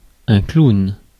Ääntäminen
Synonyymit pitre gugusse (halventava) déconneur Ääntäminen France: IPA: [klun] Tuntematon aksentti: IPA: /klon/ Haettu sana löytyi näillä lähdekielillä: ranska Käännös Substantiivit 1. clown 2. antic Suku: m .